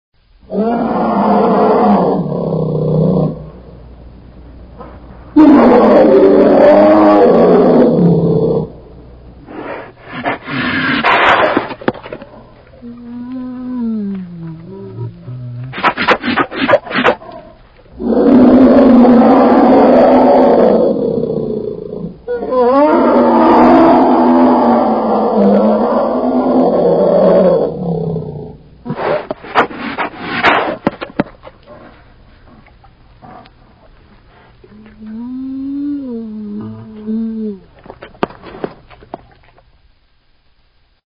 Bear Roar Download
bear.MP3